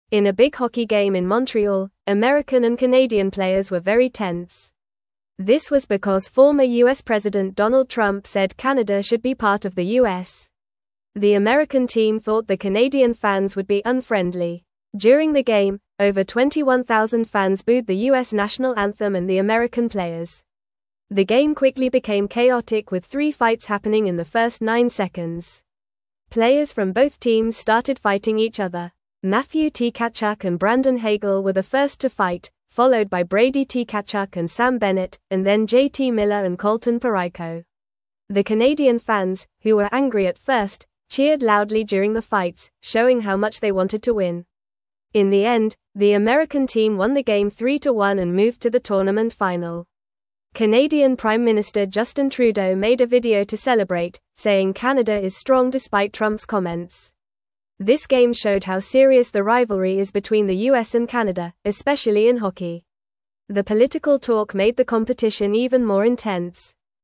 English-Newsroom-Lower-Intermediate-FAST-Reading-Hockey-Game-with-Fights-and-Excited-Fans.mp3